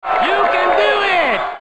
Play, download and share U can do it เสียงคน original sound button!!!!